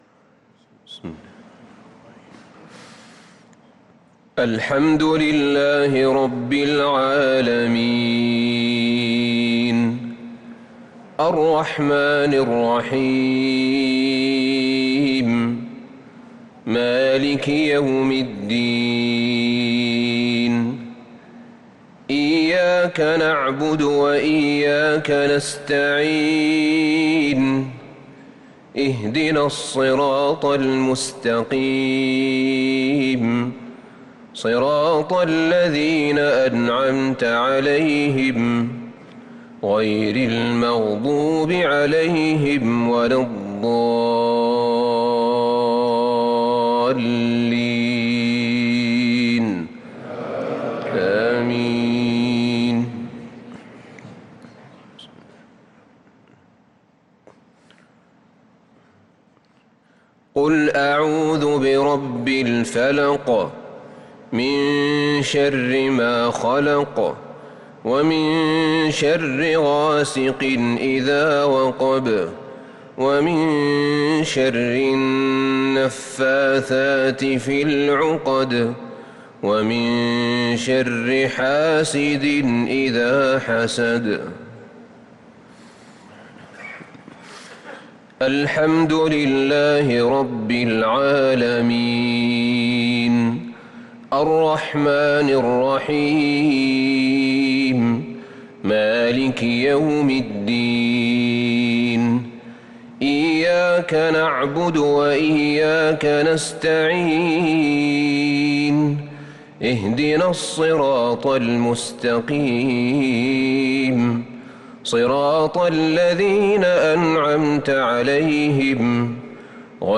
صلاة المغرب للقارئ أحمد بن طالب حميد 14 رمضان 1443 هـ